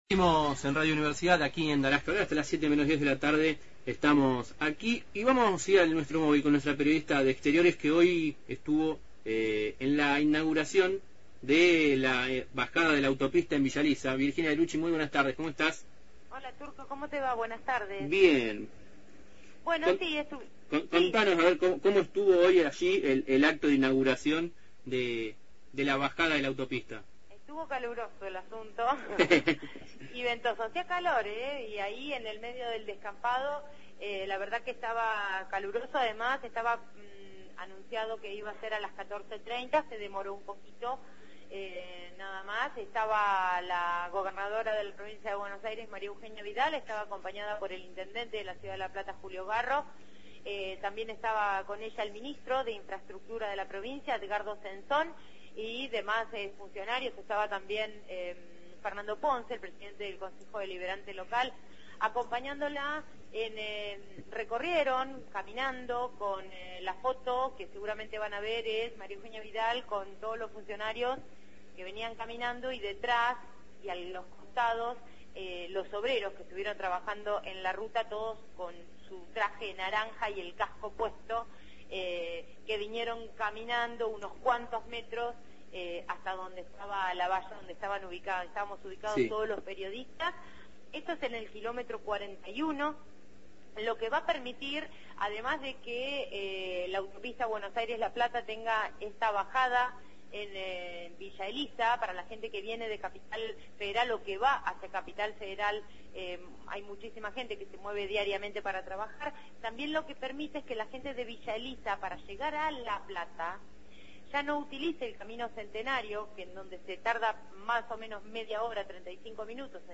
El acto se desarrolló en el kilómetro 41 de la Autopista, sentido a la Ciudad de Buenos Aires.